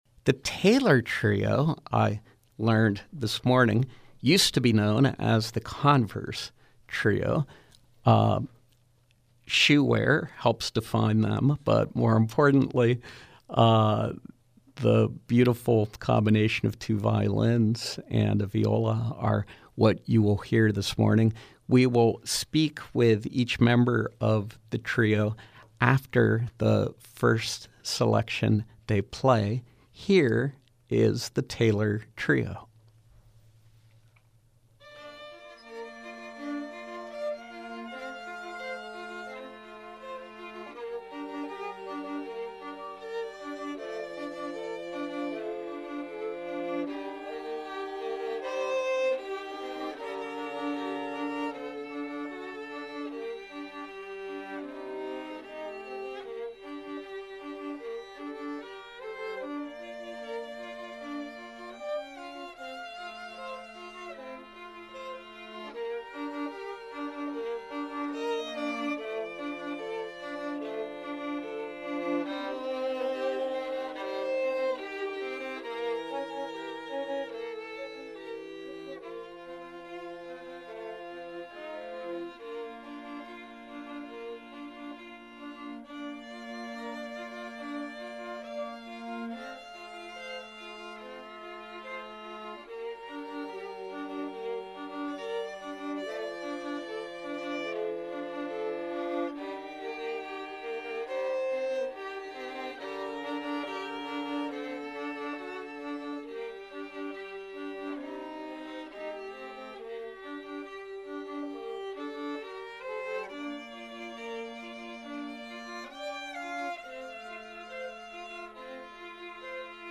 viola
violin